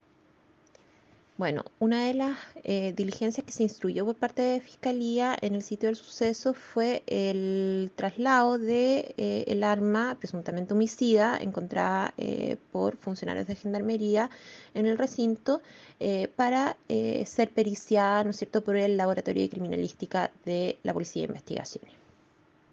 fiscal Sandra González sobre un homicidio en la cárcel de Valdivia.